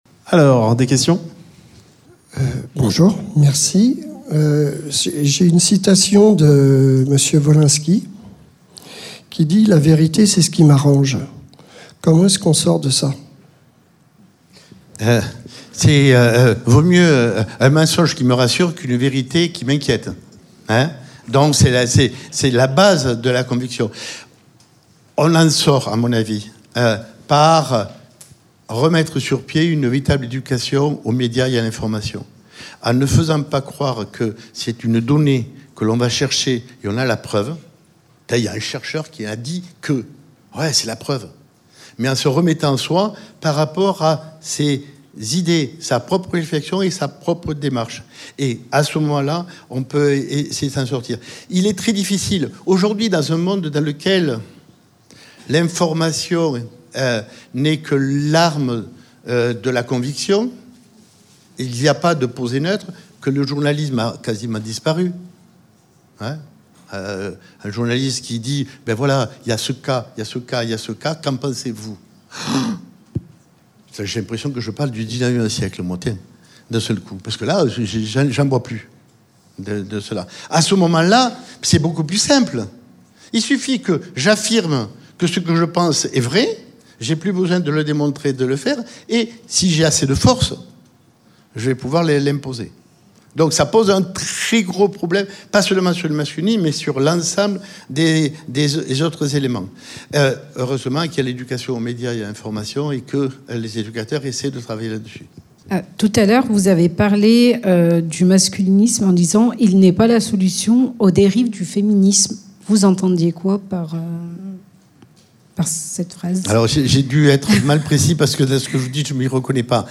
Rendez-vous réservé aux professionnels dans le cadre des Semaines de l’Égalité, la journée de formation s’est déroulée dans les locaux de l’Université Jean Monnet de Saint-Etienne le jeudi 06 Mars. Le CIDFF 42, SOS Racisme 42, Zoomacom et l’ANEF Loire organisaient une série de conférences et ateliers, en partenariat avec d’autres structures du territoire, pour décrypter le masculinisme.